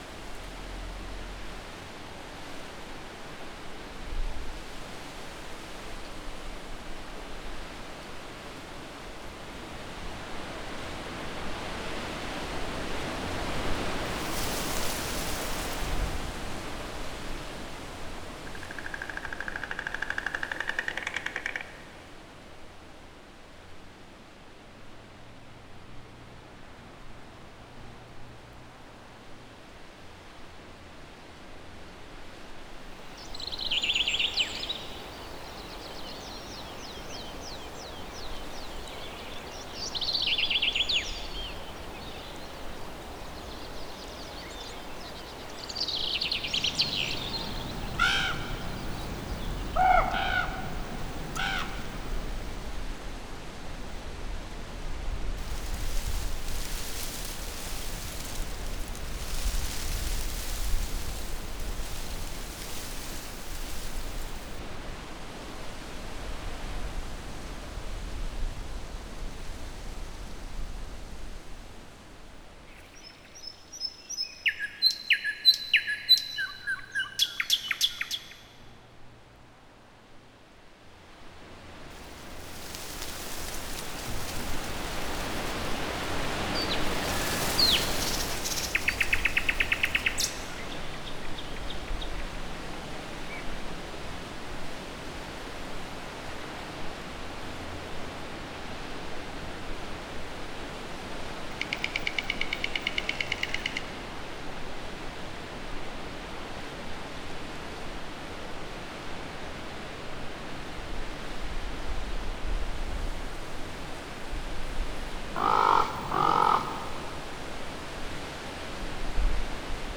Farm_Atmos_01.wav